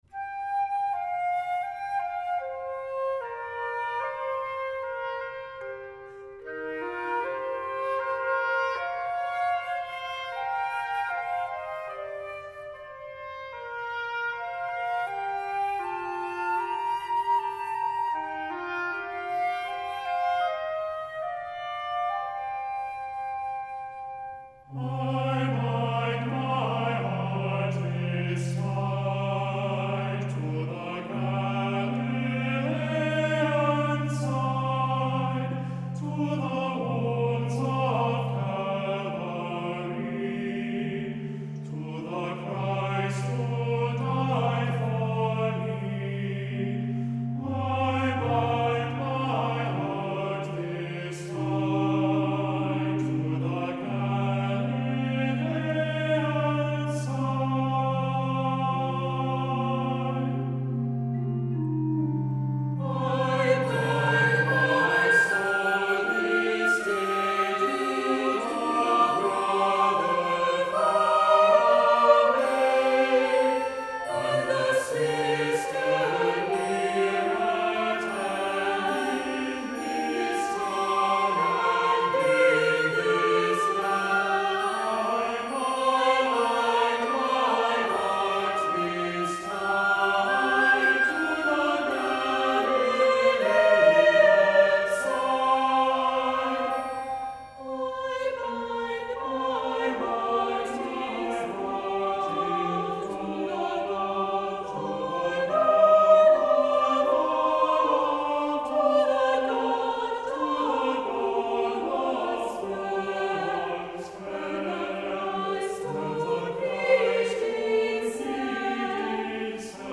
Voicing: Two-part mixed